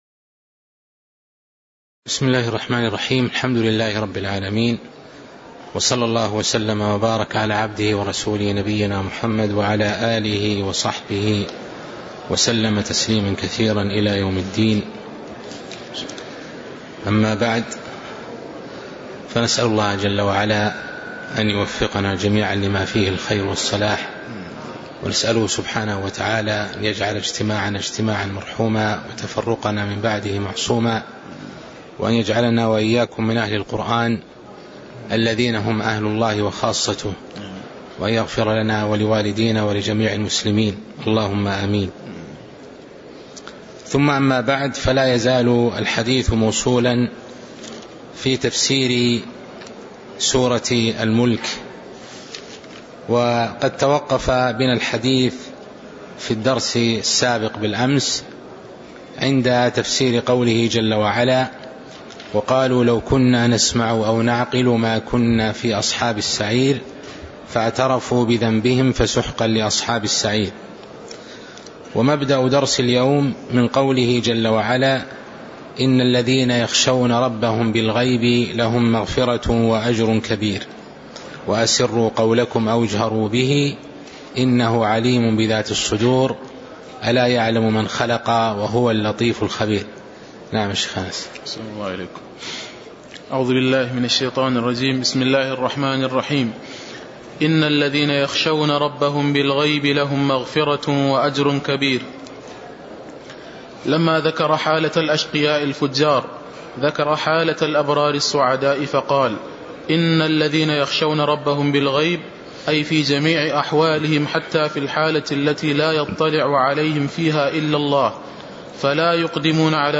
تاريخ النشر ١١ ربيع الثاني ١٤٤٣ هـ المكان: المسجد النبوي الشيخ